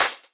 osu-logo-downbeat.wav